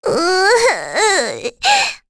Rehartna-Vox_Sad1_kr.wav